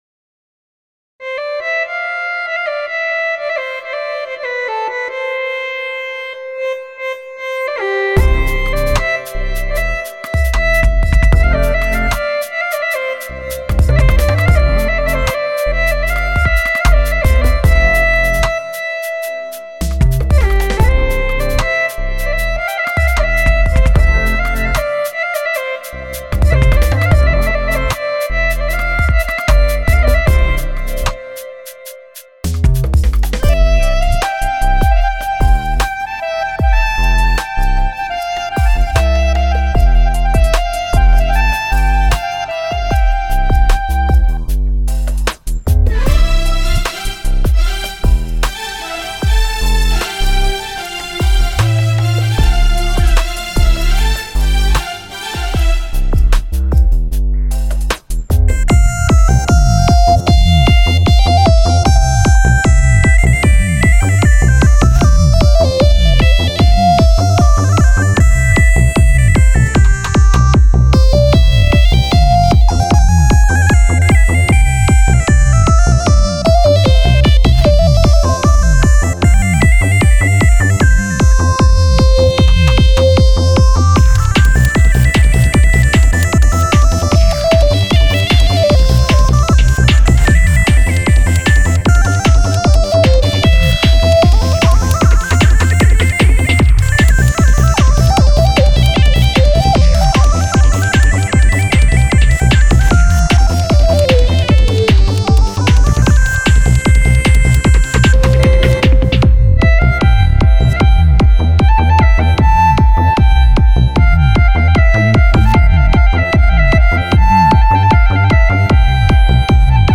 טראק אורגן קורג
ההתחלה מוזרה קצת כי אין קשר בין אקורדיון (לא אמיתי לסאונד שגם אותו צריך להחליף כי הוא עדין מידי לעומת הטראק עצמו) וצריך להנמיך קצת את האזור של ה 150הרץ עד ה600 הרץ לא יודע איך קוראים לאזור הזה כי הוא קצת צורם